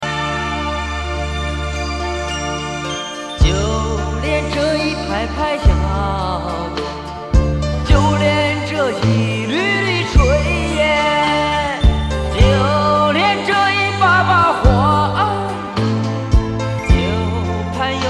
自购磁带转录，激动论坛首发